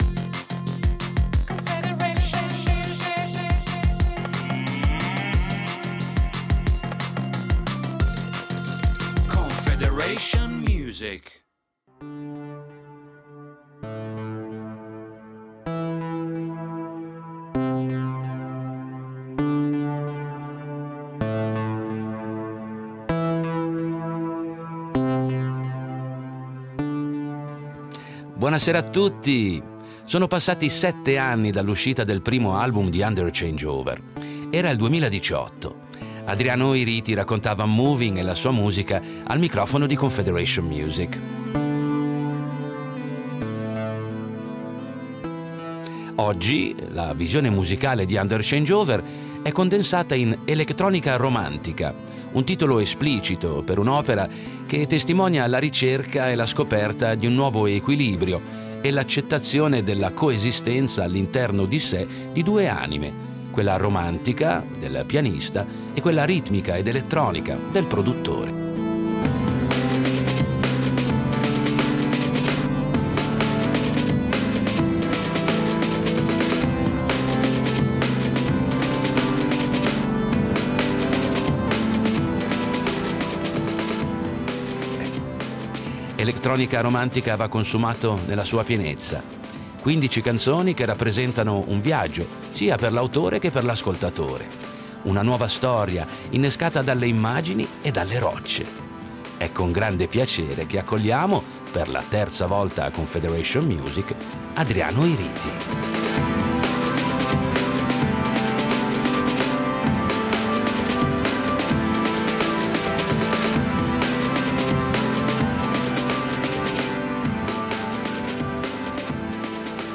Musica elettronica